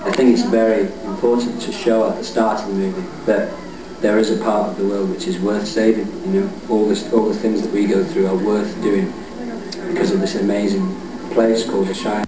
"I think it's very important" - An interview